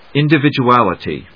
音節in・di・vid・u・al・i・ty 発音記号・読み方
/ìndəvìdʒuˈæləṭi(米国英語), ˌɪˌndɪvɪdʒu:ˈælɪti:(英国英語)/